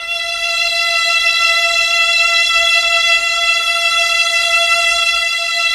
Index of /90_sSampleCDs/Keyboards of The 60's and 70's - CD1/KEY_Chamberlin/STR_Chambrln Str